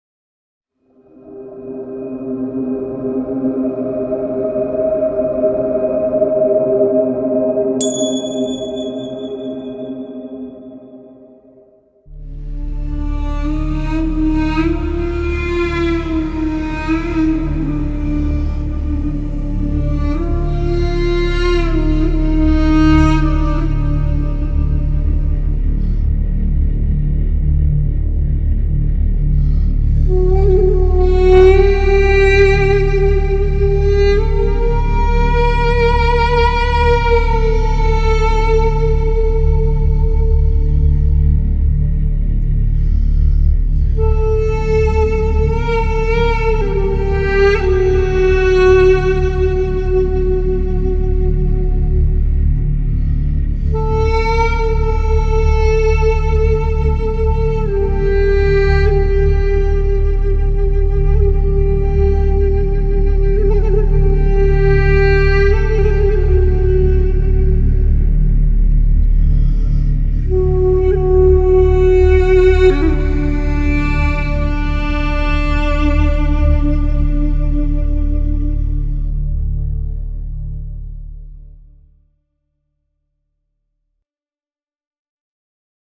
Film Trailer/World: Middle Eastern